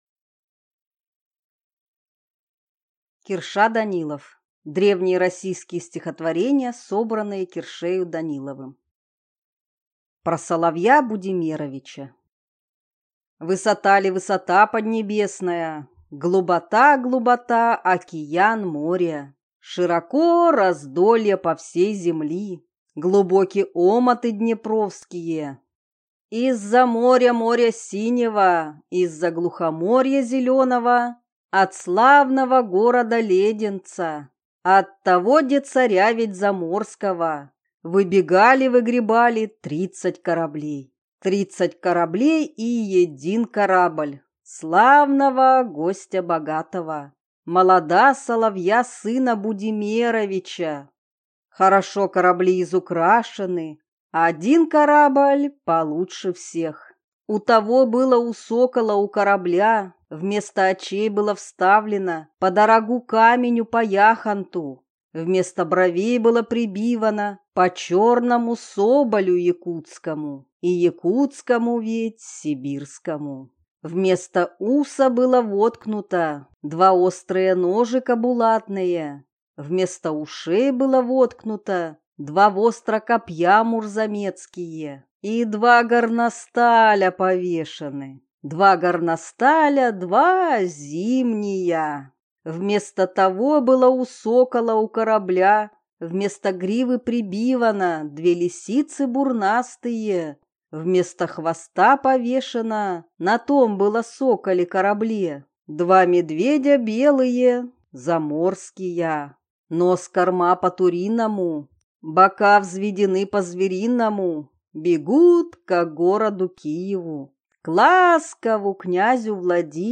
Аудиокнига Древние российские стихотворения, собранные Киршею Даниловым | Библиотека аудиокниг